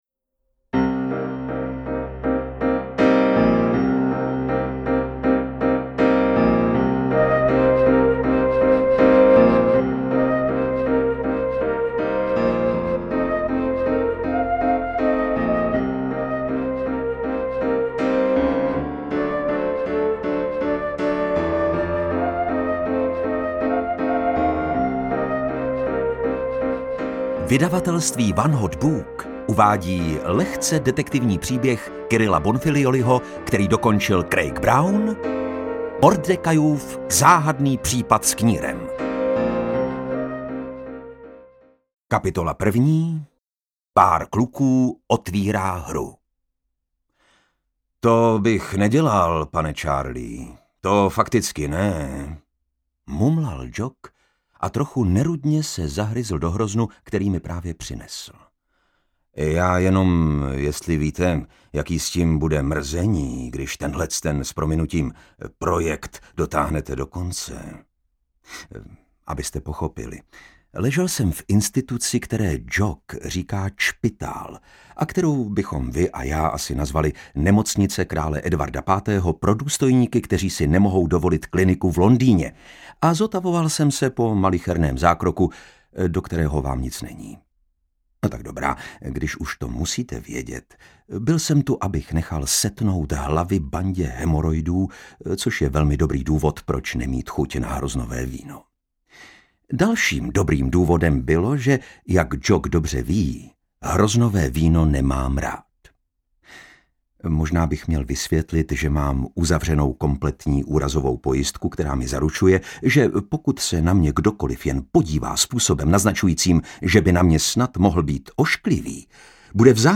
Interpret:  Lukáš Hlavica